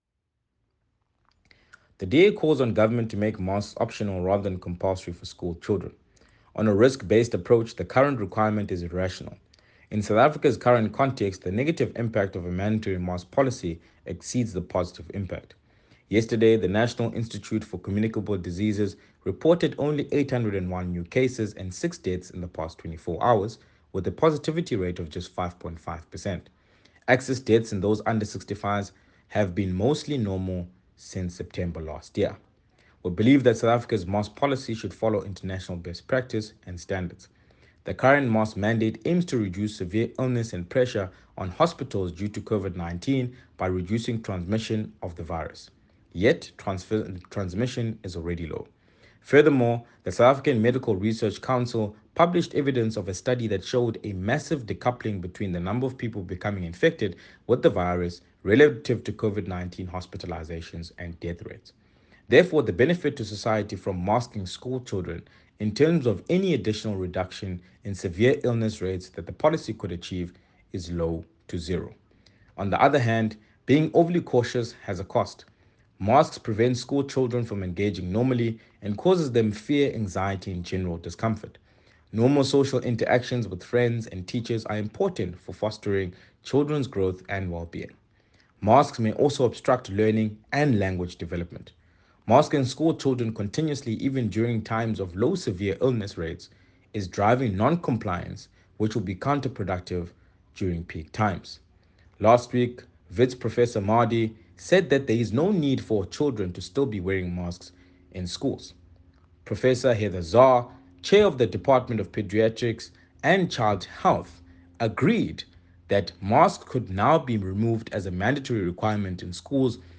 soundbite by Baxolile ‘Bax’ Nodada MP.